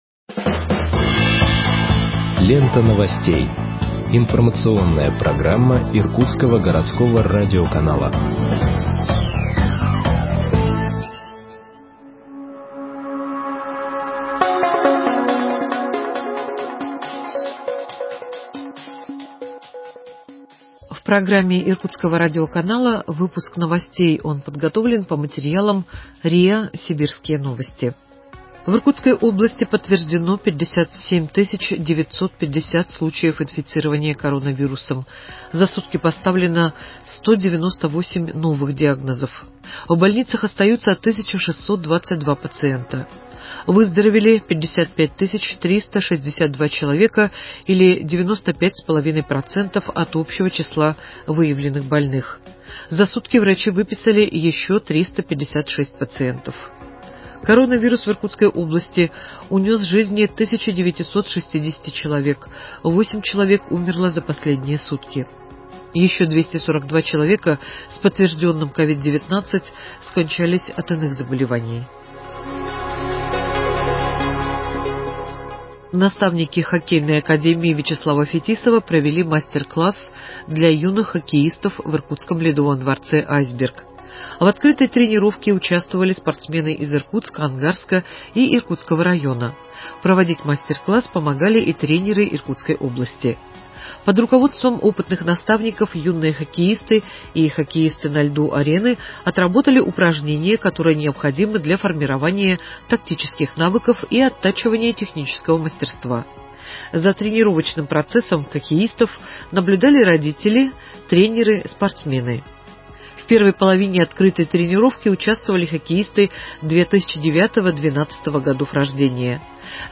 Выпуск новостей в подкастах газеты Иркутск от 10.03.2021 № 2